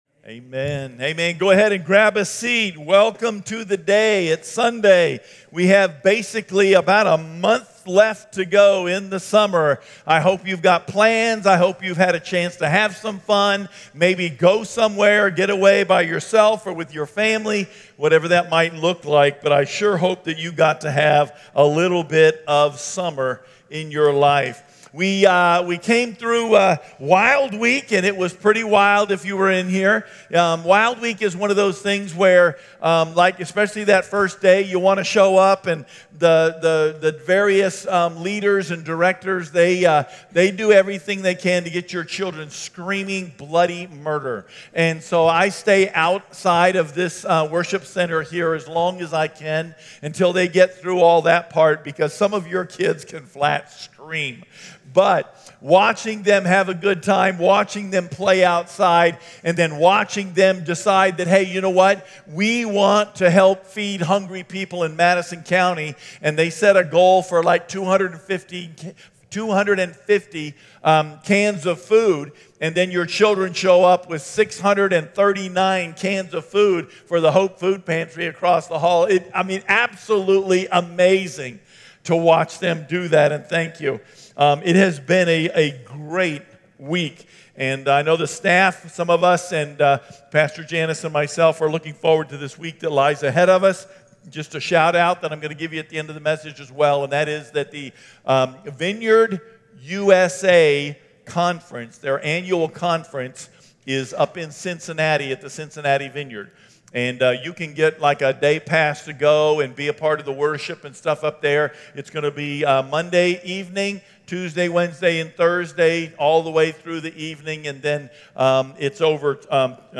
… continue reading 300 odcinków # Religion # Jesus # Vineyard # Richmond # Bible # Vineyard Community Church Richmond # Christianity # Sermons # Messages